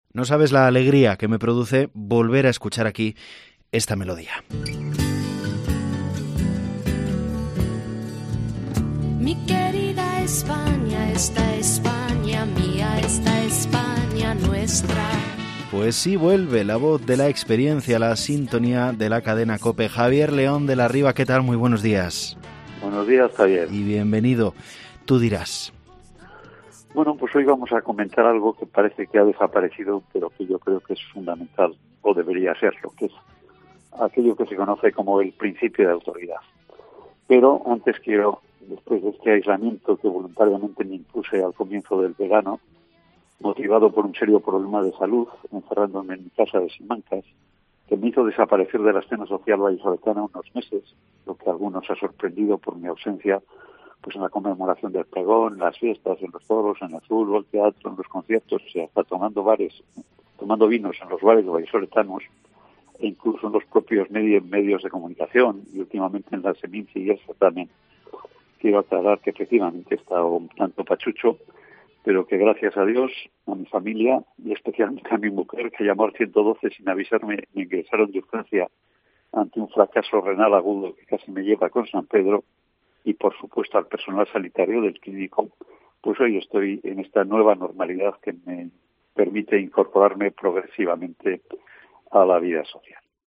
Javier León de la Riva retoma su comentario semanal en COPE Valladolid después de un "serio" problema de salud
Ha sido el propio De la Riva quien ha querido trasladar a los oyentes de Mediodía COPE Valladolid que “efectivamente, he estado un tanto pachucho”.